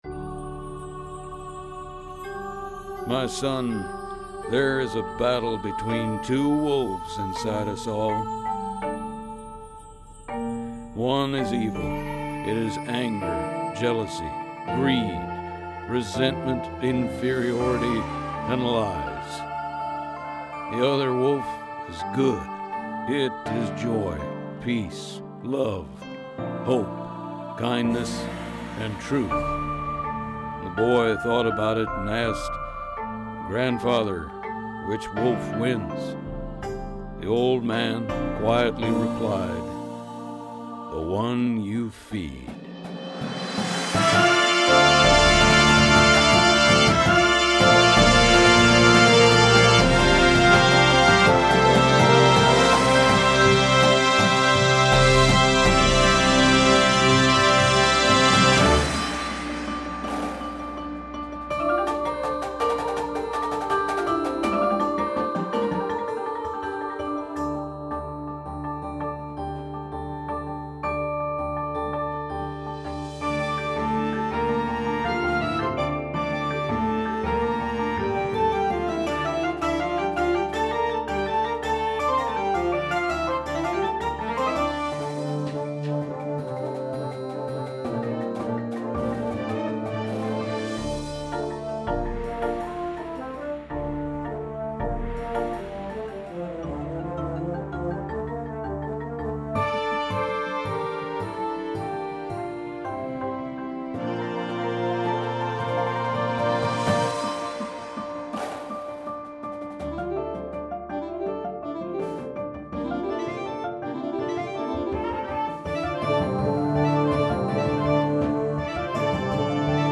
Full Show
• Flute
• Clarinet 1, 2
• Alto Sax 1, 2
• Trumpet 1
• Horn in F
• Trombone 1, 2
• Tuba
• Snare Drum
• Sound Effect Samples
• Marimba – Two parts
• Vibraphone – Two parts